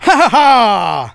el_primo_kill_03.wav